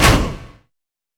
FX - Stomp 3.wav